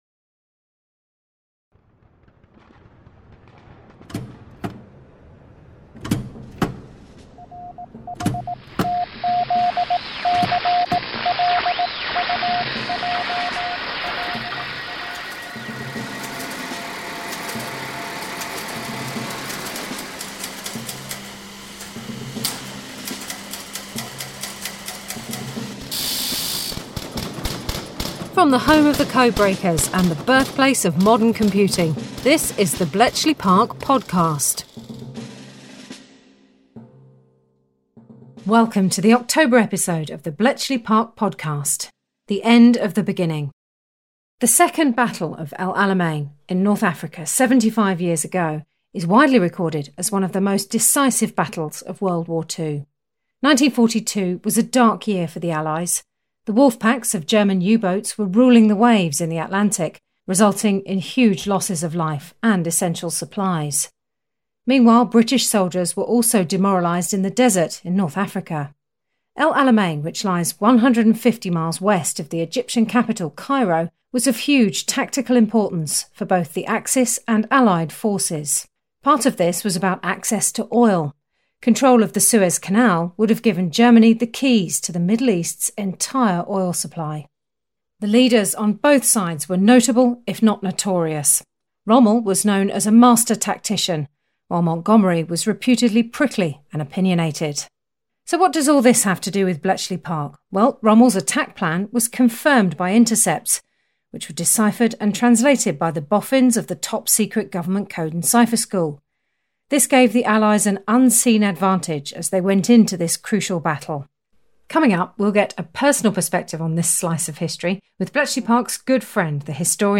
Rommel’s attack plan was confirmed by intercepts which were deciphered and translated by the top secret Government Code and Cipher School at Bletchley Park, giving the Allies an unseen advantage. In this episode, we bring you a personal perspective on this slice of history, with Bletchley Park’s good friend, the historian Dan Snow.